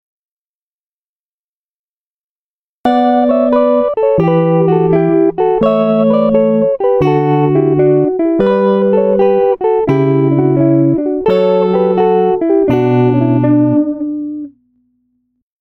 music(guitar).mp3